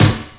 SINGLE HITS 0007.wav